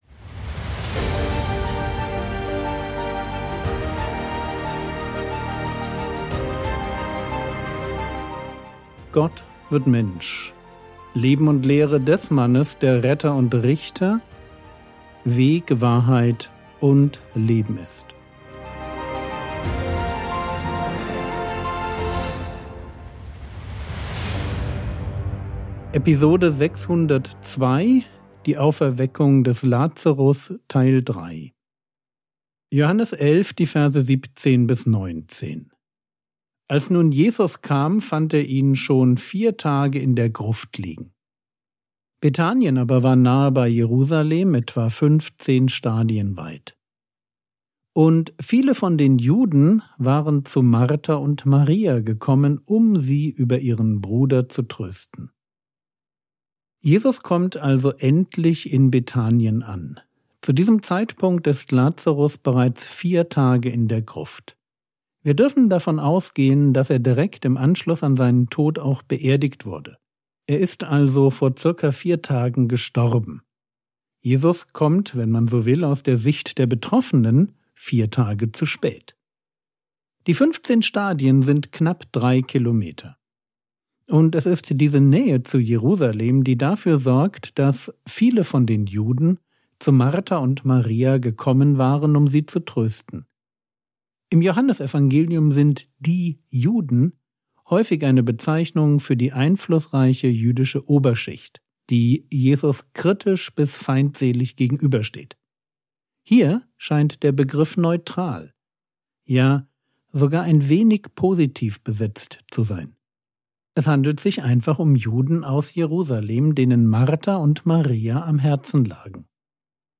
Episode 602 | Jesu Leben und Lehre ~ Frogwords Mini-Predigt Podcast